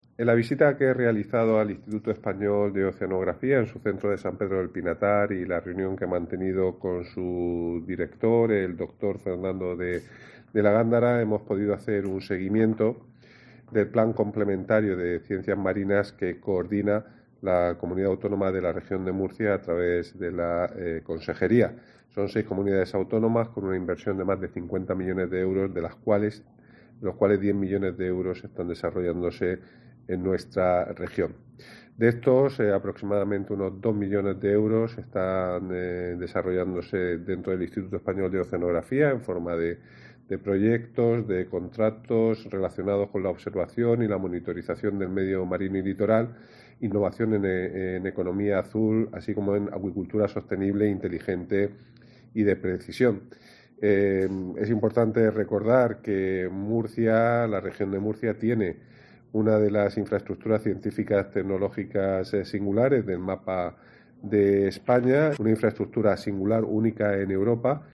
Juan María Vázquez, consejero de Medio Ambiente, Mar Menor, Universidades e Investigación